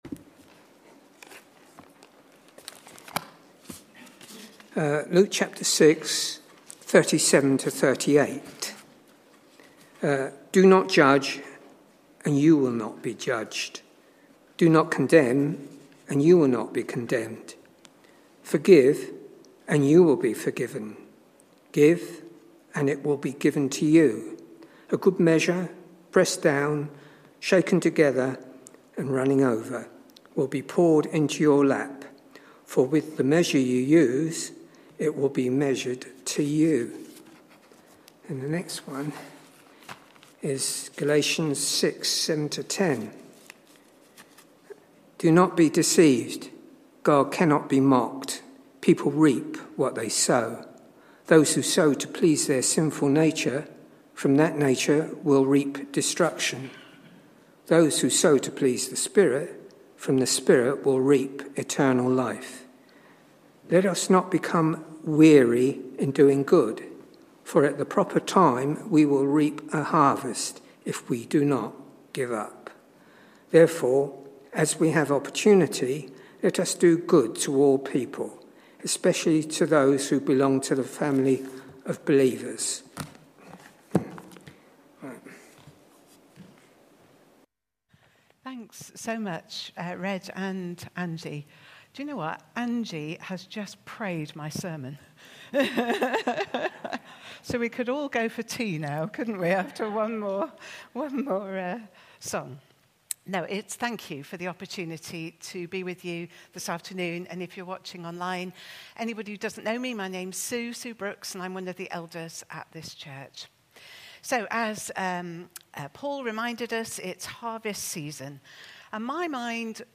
A talk from the series "Traditional Service."
Messages from our Traditional Services